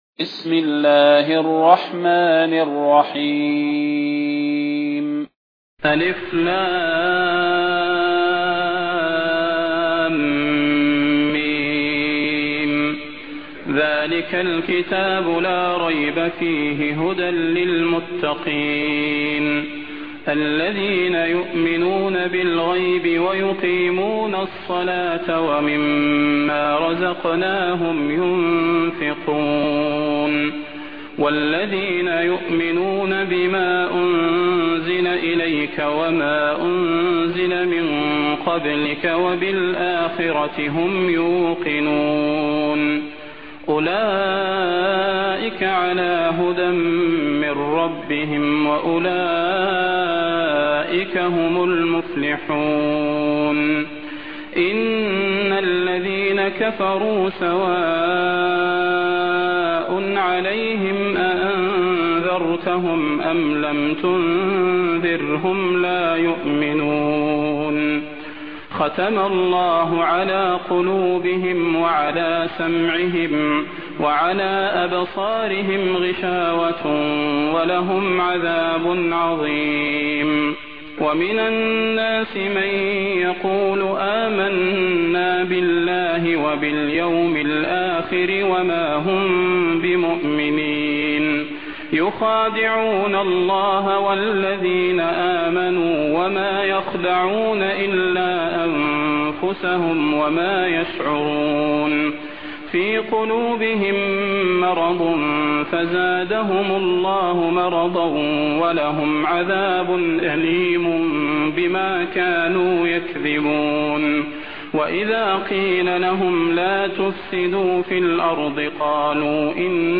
المكان: المسجد النبوي الشيخ: فضيلة الشيخ د. صلاح بن محمد البدير فضيلة الشيخ د. صلاح بن محمد البدير البقرة